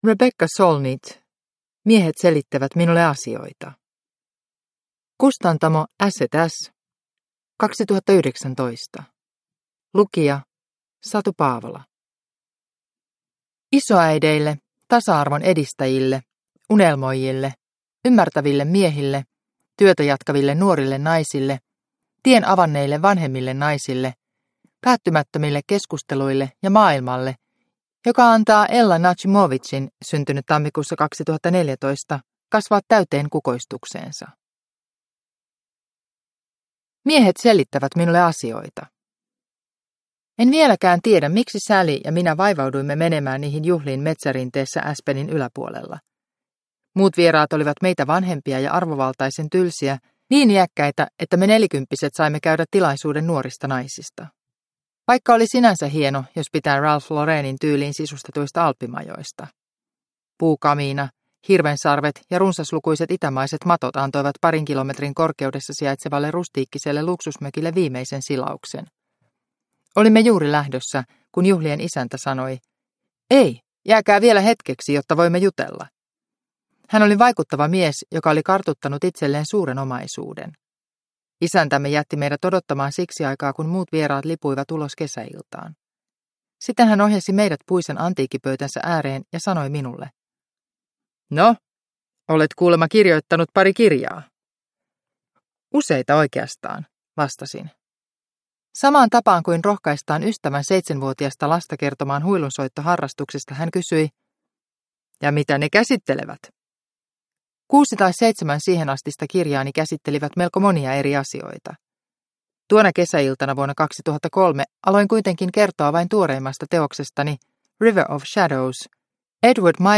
Miehet selittävät minulle asioita – Ljudbok – Laddas ner